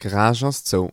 garage_closed.mp3